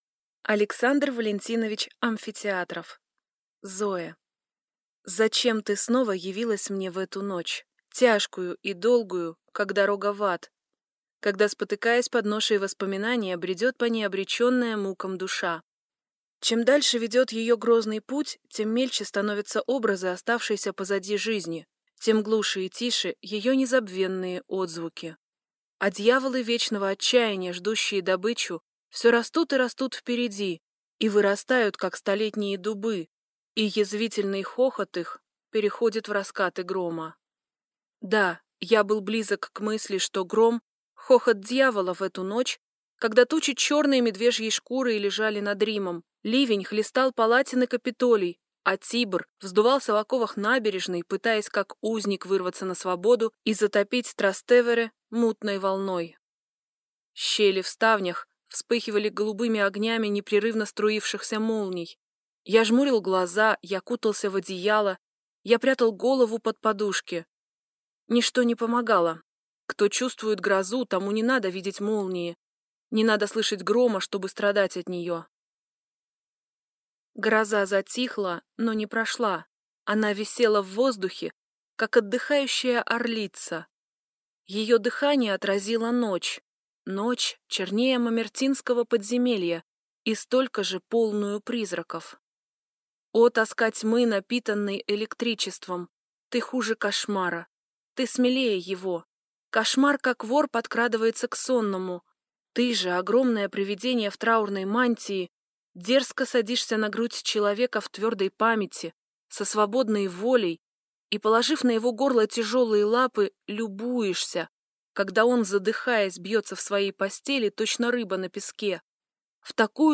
Аудиокнига Зоэ | Библиотека аудиокниг
Прослушать и бесплатно скачать фрагмент аудиокниги